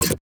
Universal UI SFX / Clicks
UIClick_Menu Navigation Short 01.wav